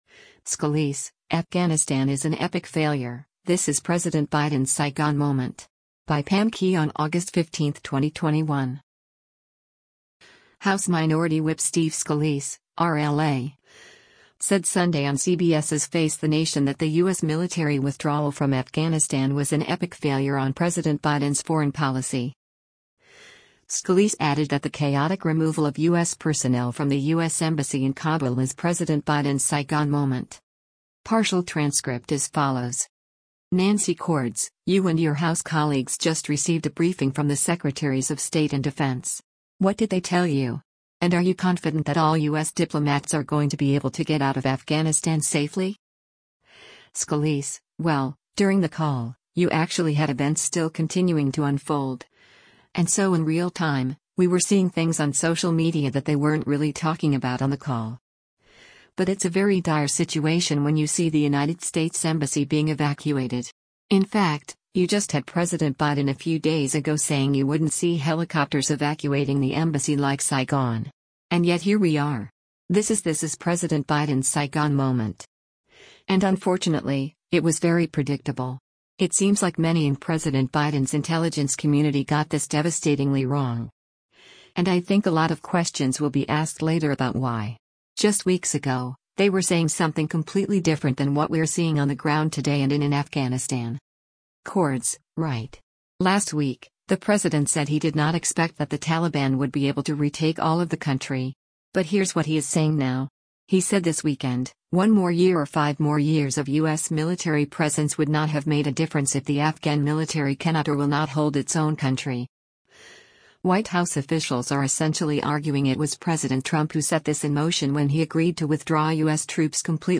House Minority Whip Steve Scalise (R-LA) said Sunday on CBS’s “Face the Nation” that the U.S. military withdrawal from Afghanistan was “an epic failure on President Biden’s foreign policy.”